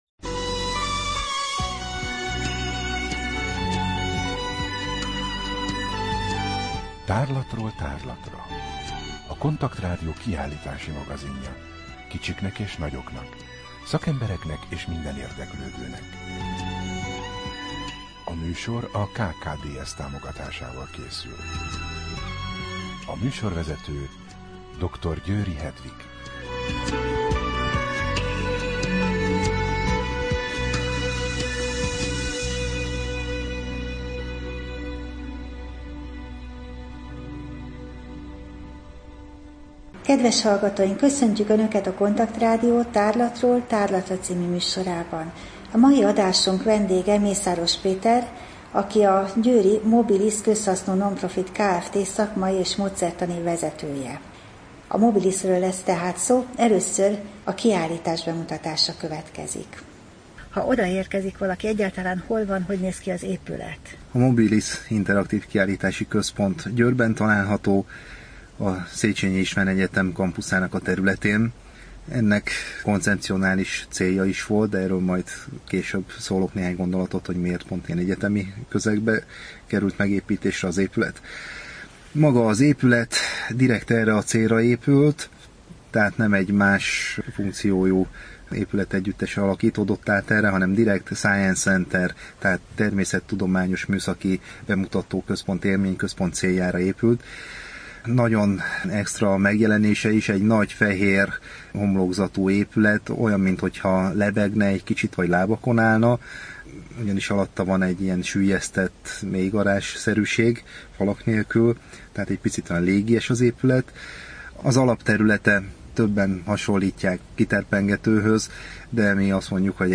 Rádió: Tárlatról tárlatra Adás dátuma: 2013, September 23 Tárlatról tárlatra / KONTAKT Rádió (87,6 MHz) 2013. szeptember 23. A műsor felépítése: I. Kaleidoszkóp / kiállítási hírek II. Bemutatjuk / Mobilis, Győr A műsor vendége